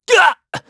Siegfried-Vox_Damage_kr_02.wav